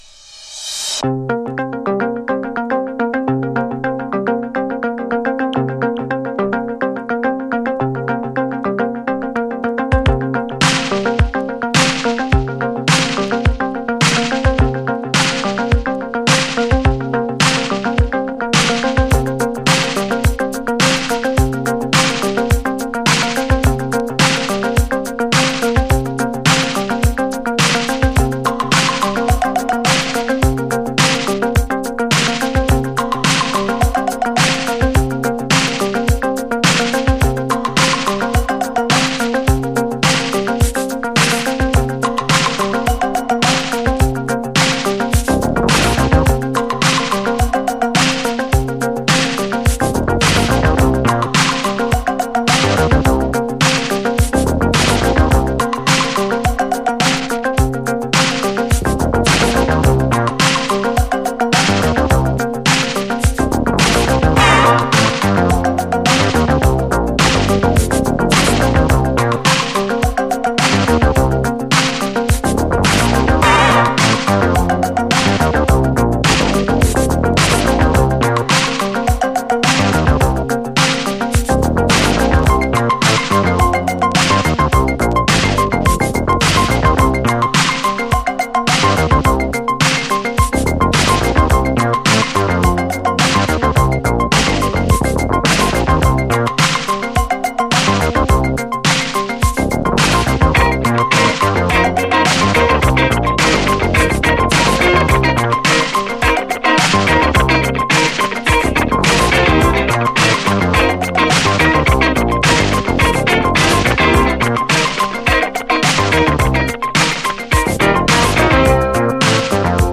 DISCO
フレンチ・ブギー〜コズミック・クラシック！異様な空気感に息を飲むキラー・トラック！